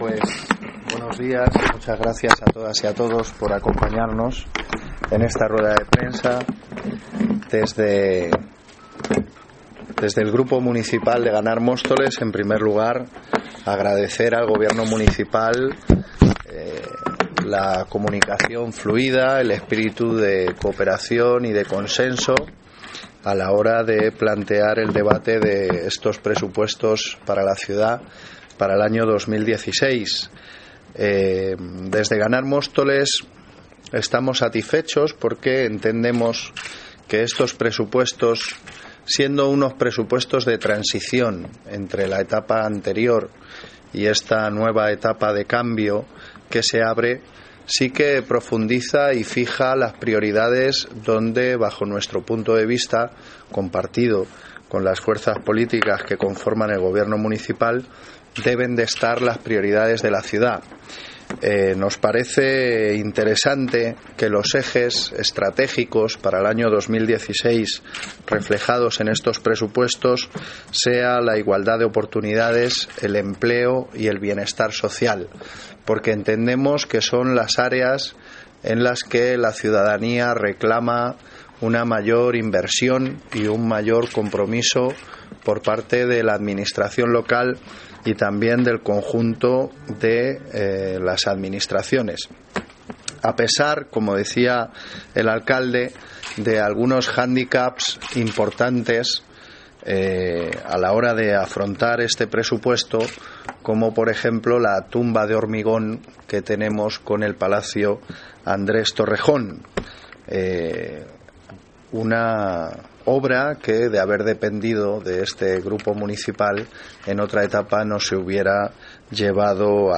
Audio - Gabriel Ortega (Portavoz del grupo Ganar Móstoles) Sobre Presupuestos